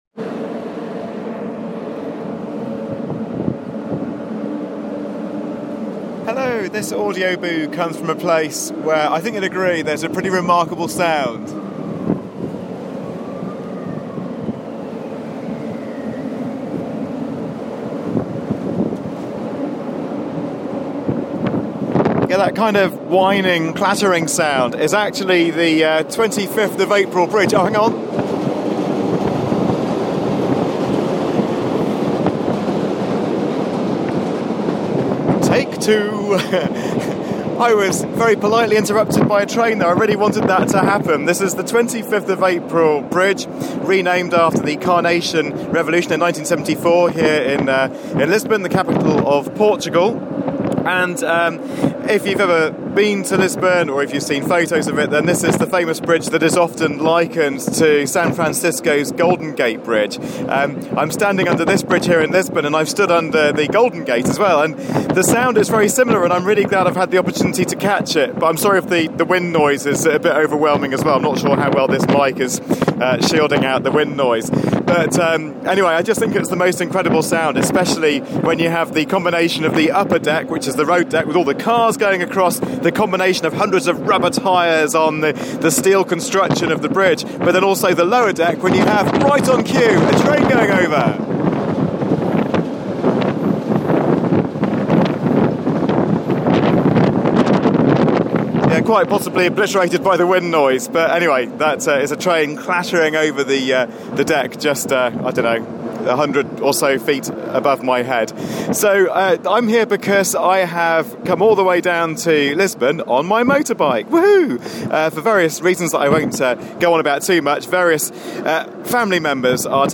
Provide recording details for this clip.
After 1800 miles on the motorbike I'm in Bootiful Lisbon. Here are my travellers tales and some sounds from a very famous bridge